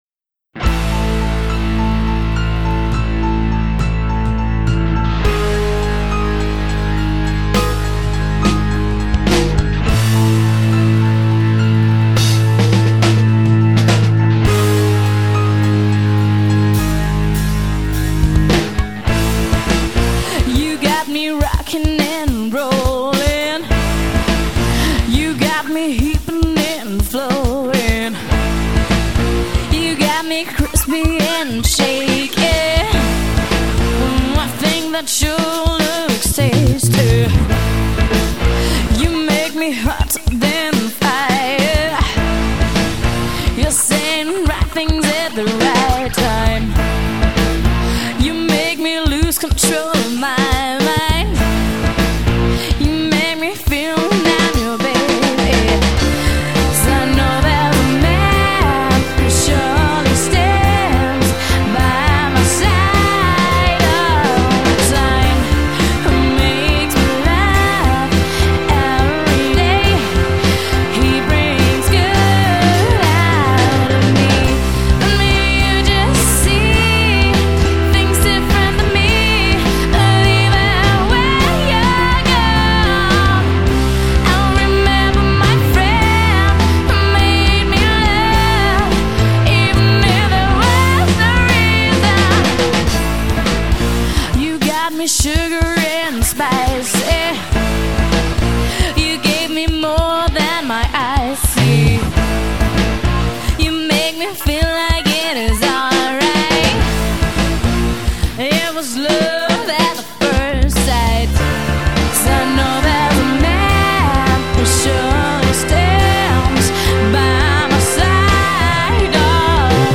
Pop/Rock bis Soul
piano
drums